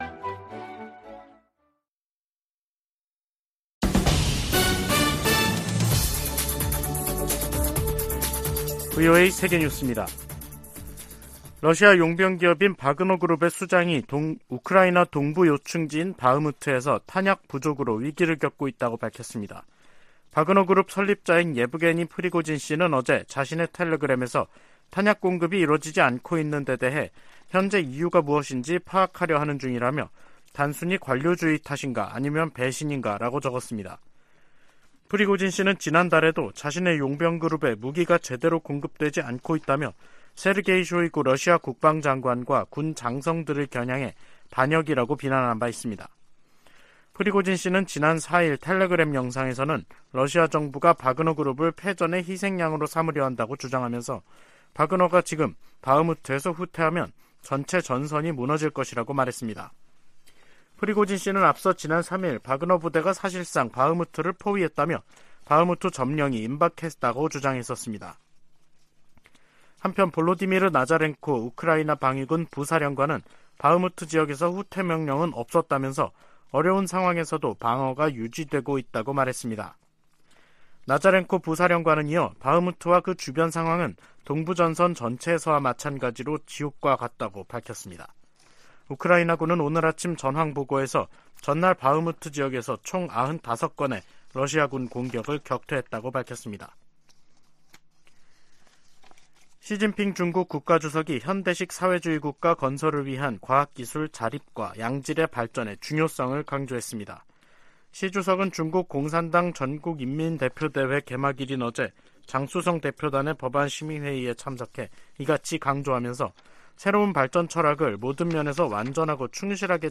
VOA 한국어 간판 뉴스 프로그램 '뉴스 투데이', 2023년 3월 6일 3부 방송입니다. 한국 정부가 일제 강제징용 해법으로 피해자들에게 국내 재단이 대신 배상금을 지급한다는 결정을 내렸습니다.